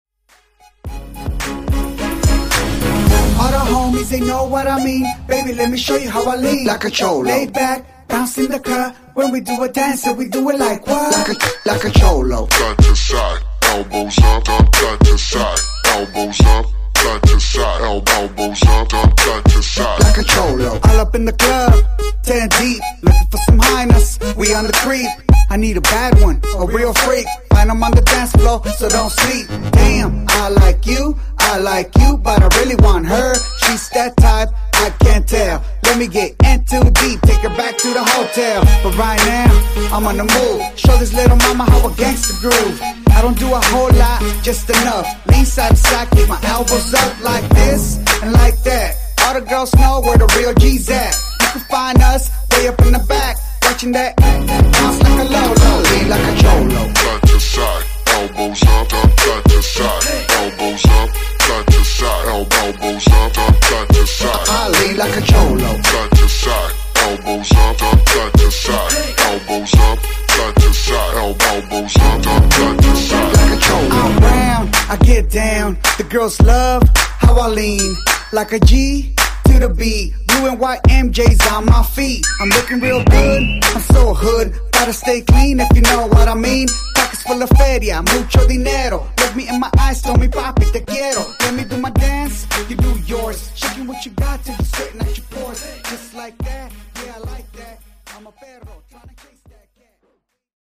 Genre: RE-DRUM Version: Clean BPM: 51 Time